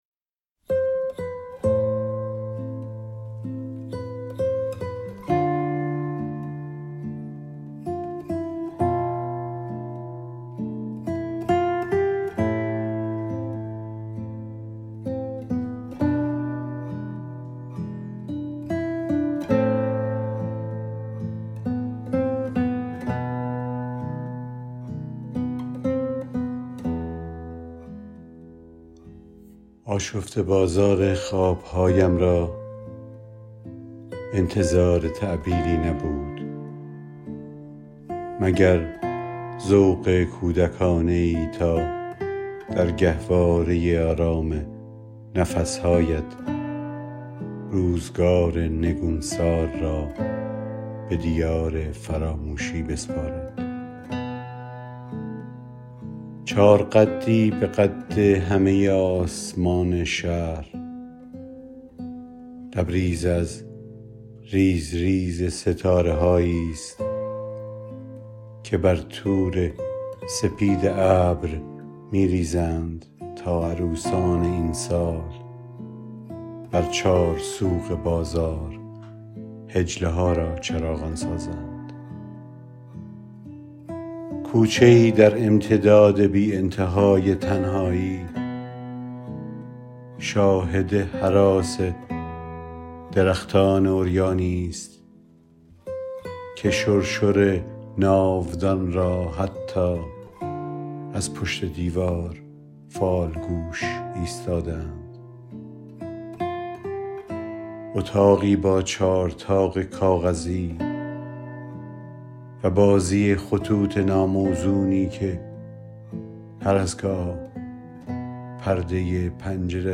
این شعر را با صدای شاعر از این جا بشنوید